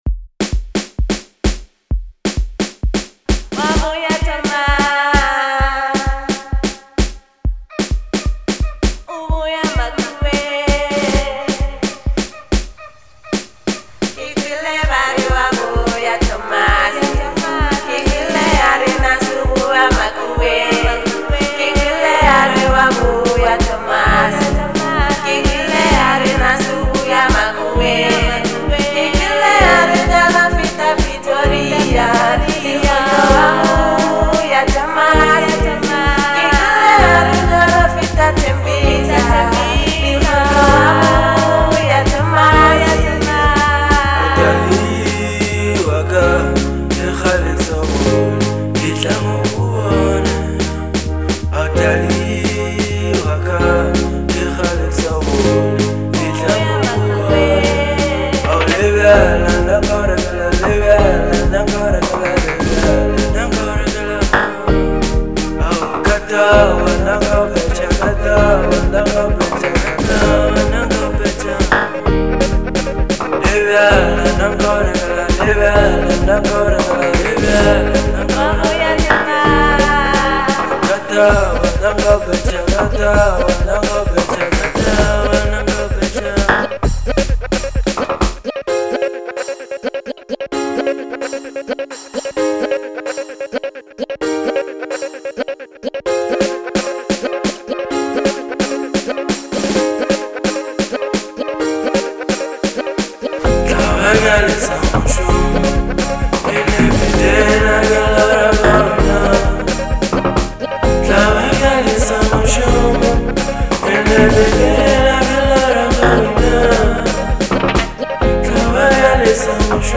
Genre : Manyalo